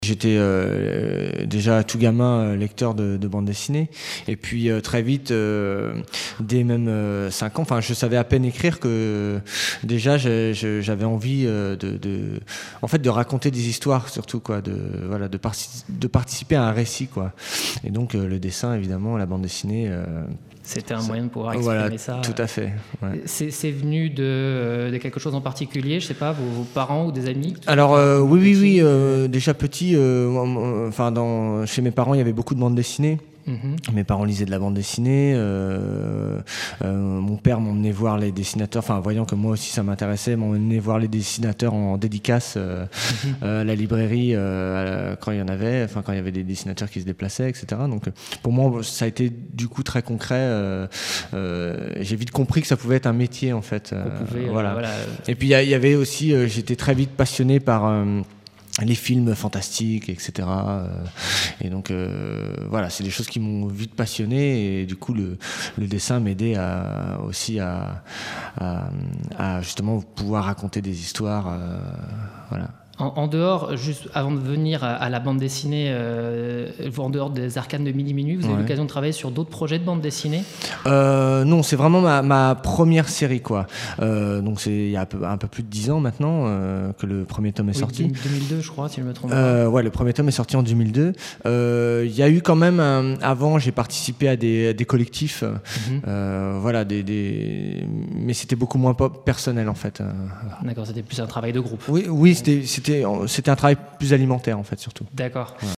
Emission enregistrée au Printemps du livre le 28 mai 2015
Témoignage